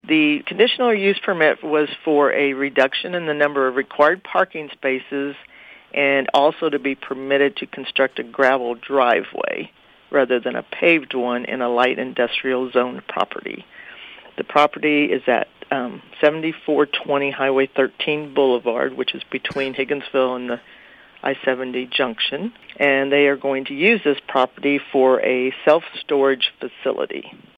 City Administrator Jeanette Dobson describes the purpose of the request.